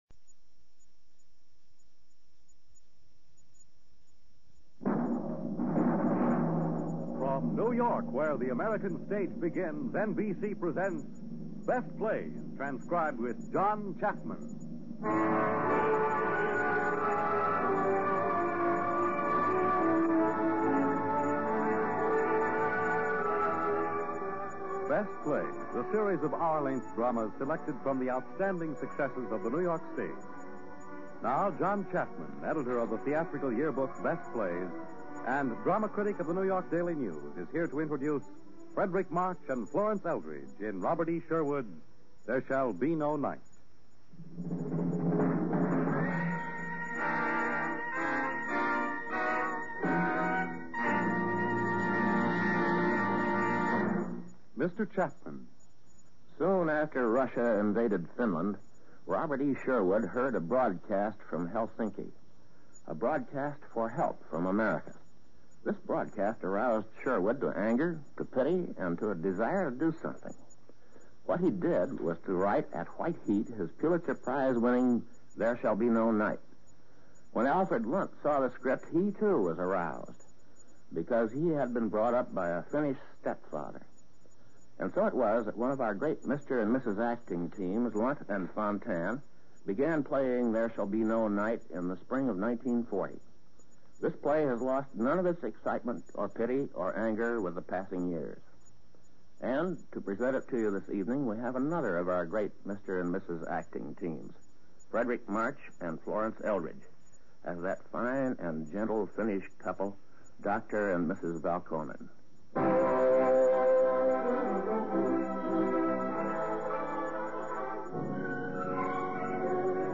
Going on-air from 1952 to 1953, the Best Plays was an NBC Radio program that featured some of the most excellent theatric plays ever created. Some of the best ones featured were dramatic or comedic plays.